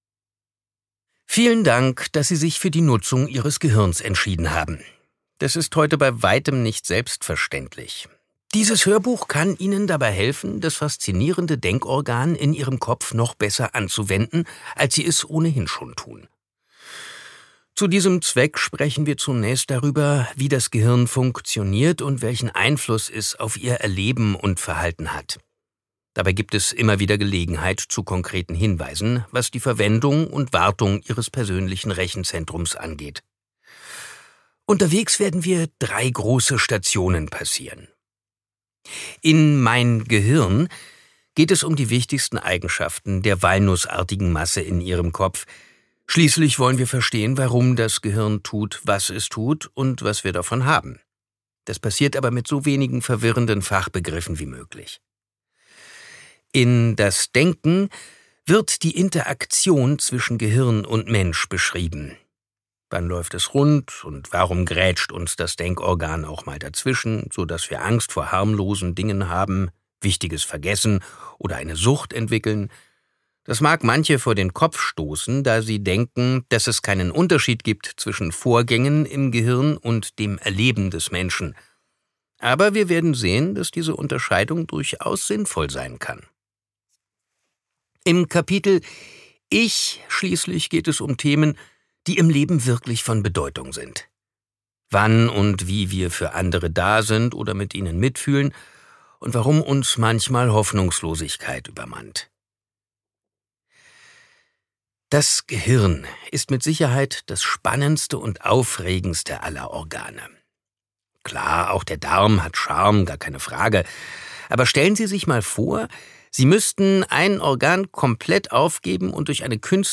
Gekürzt Autorisierte, d.h. von Autor:innen und / oder Verlagen freigegebene, bearbeitete Fassung.
Mein Gehirn, das Denken und ich Gelesen von: Simon Jäger